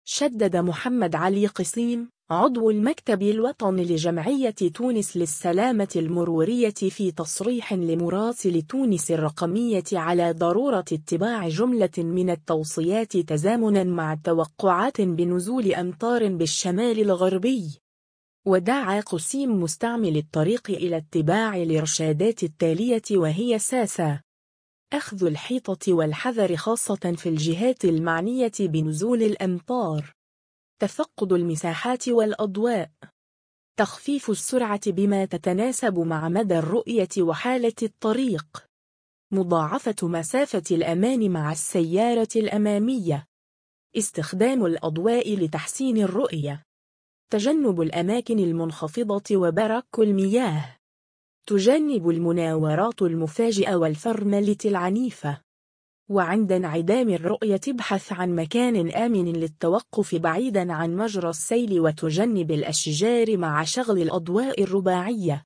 في تصريح لمراسل “تونس الرقمية”